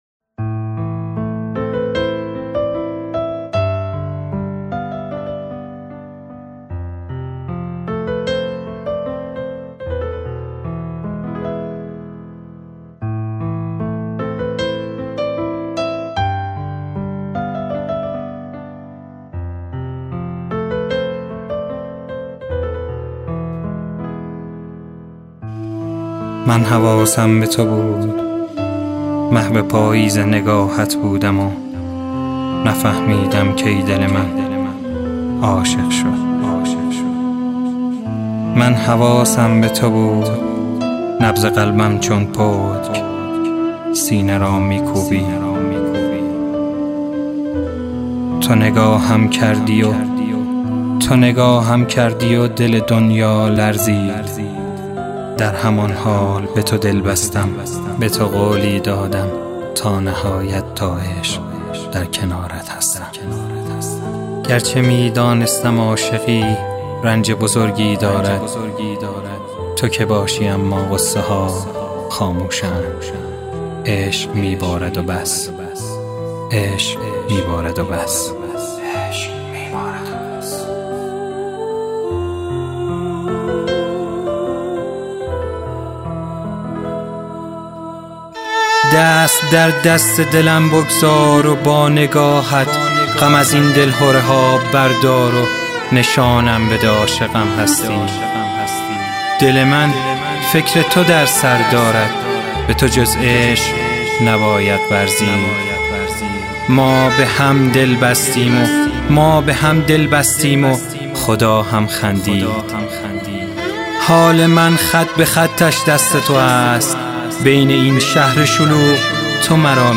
شعر نیمایی